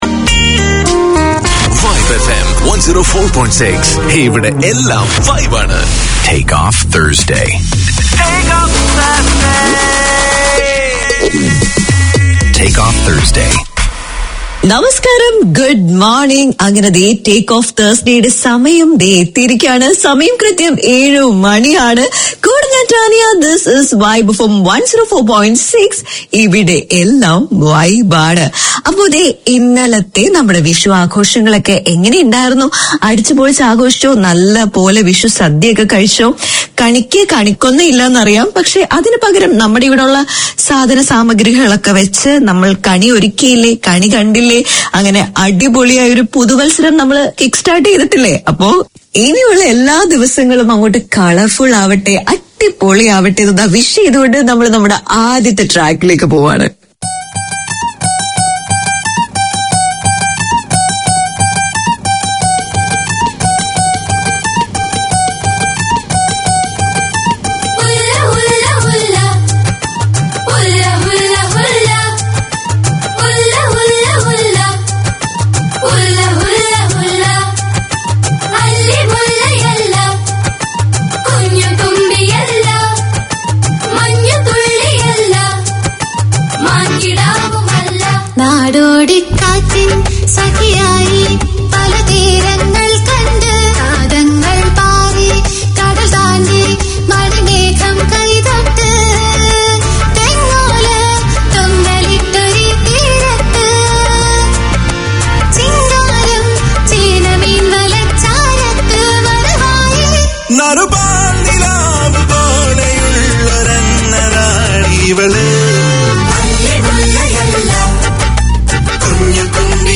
Community Access Radio in your language - available for download five minutes after broadcast.
A celebration of Malayalam language, literature, music and culture; Malayalam FM presents three weekly programmes. Hear dramas and stories based on Malayalam songs on Fridays, film and music on Saturdays, and enjoy a talk-based show featuring discussions and interviews on the Sunday edition.